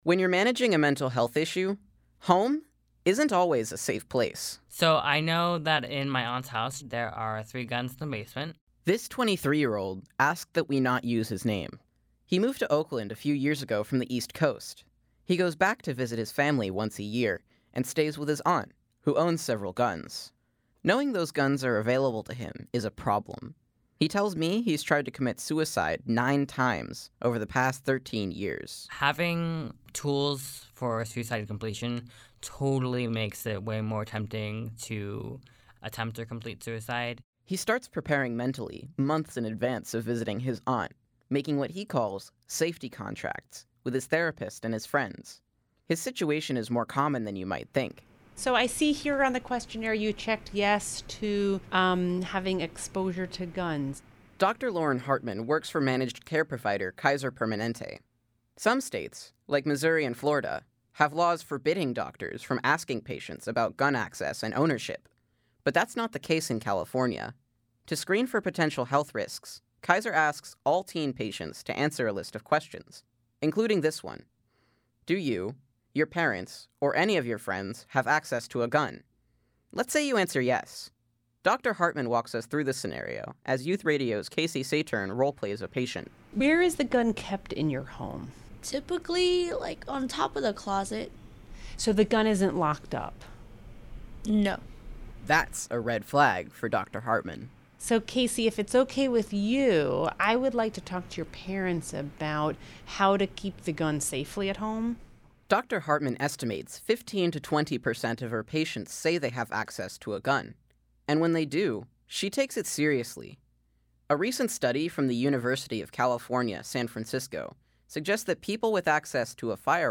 I recently talked with a 23-year-old man in Oakland, Calif., who says he’s worried about an upcoming visit to his aunt’s home on the East Coast.